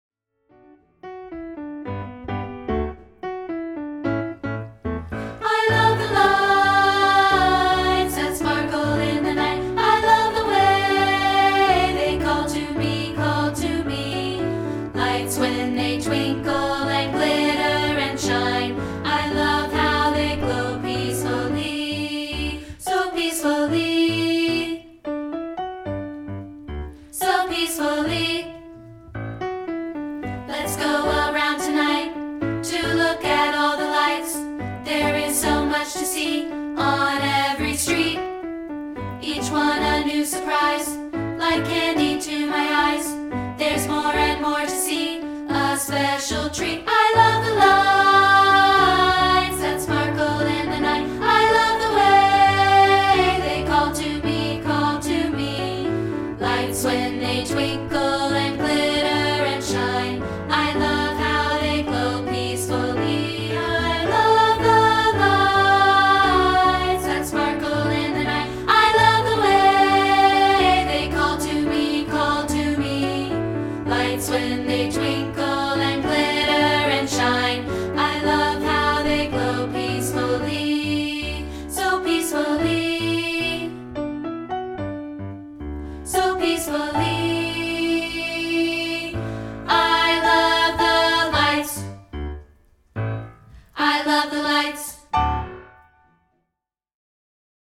This is a rehearsal track of part 2, isolated.